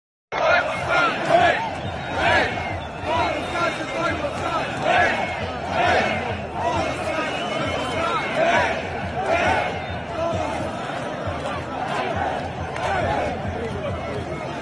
Während der gewöhnliche Ukrainer in/um Bachmut in den Gräben verreckt, springen die privilegierten Ukrainer lustig im Londoner Stadion, wo das Spiel zwischen den Fussballnationalmannschaften Englands und der Ukraine stattfand.